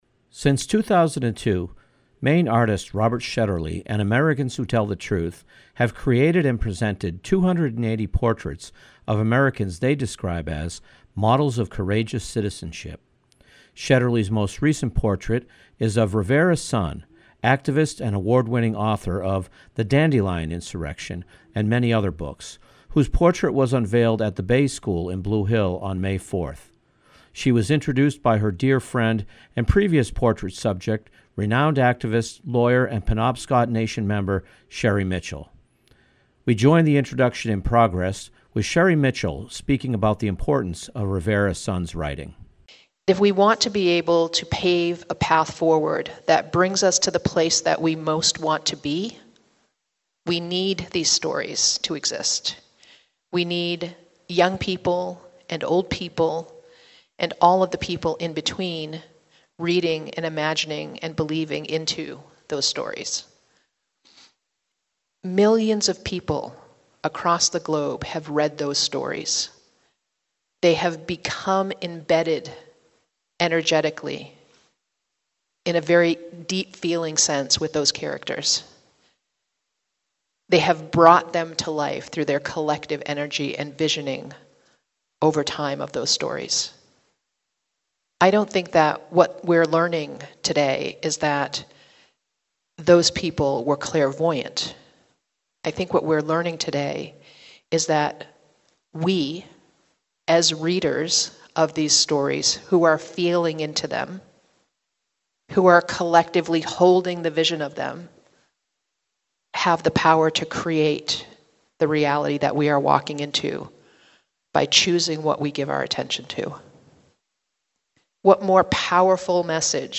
This panel discussion was co-hosted by the Greater Bangor Area Branch of the NAACP and the University of Maine Alumni Association (UMAA) as part of eastern and central Maine’s 2022 Martin Luther King Jr. Day celebration January 17, 2022. The entire event was streamed live on YouTube and is available in the UMAA YouTube archive.